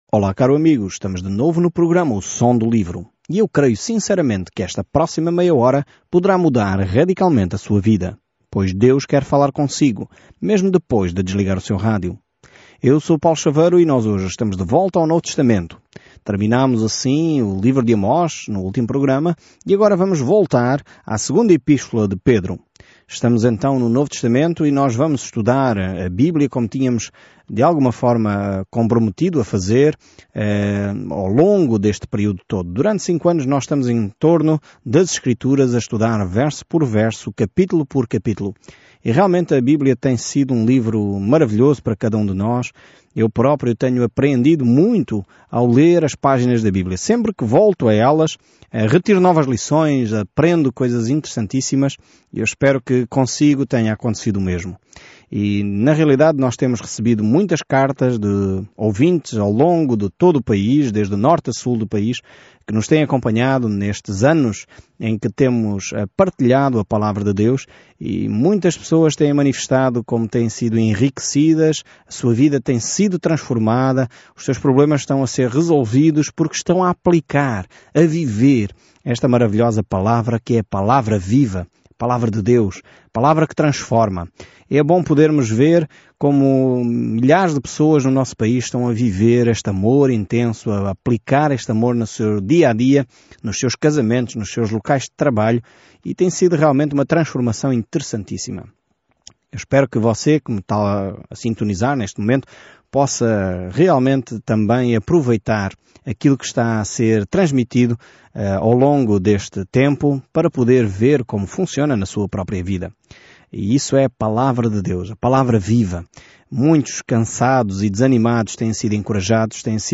Escritura 2 PEDRO 1:1 Iniciar este Plano Dia 2 Sobre este plano A segunda carta de Pedro é toda sobre a graça de Deus – como ela nos salvou, como nos mantém e como podemos viver nela – apesar do que os falsos mestres dizem. Viaje diariamente por 2 Pedro enquanto ouve o estudo em áudio e lê versículos selecionados da palavra de Deus.